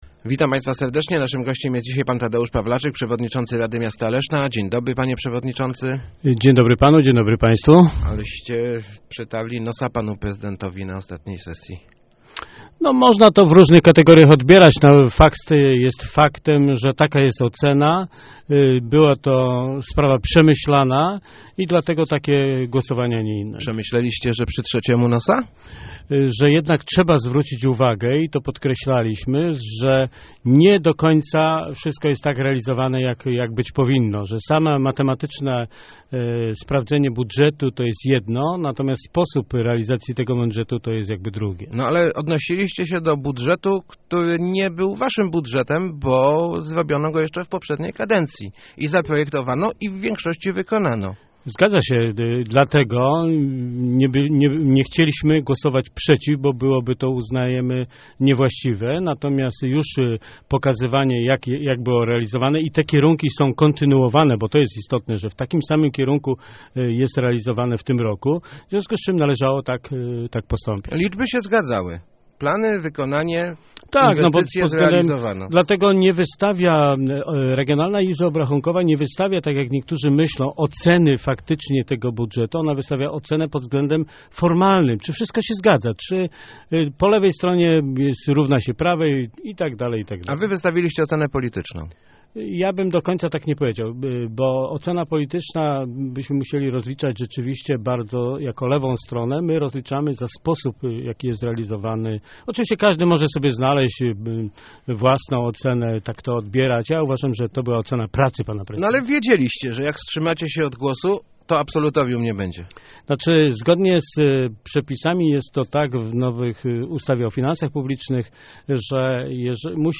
Pan Malepszy musi zrozumieć, że to nie on rządzi miastem - mówił w Rozmowach Elki Tadeusz Pawlaczyk, przewodniczący Rady Miasta Leszna. Jak ujawnia, to prezydent sam deklarował konfrontację.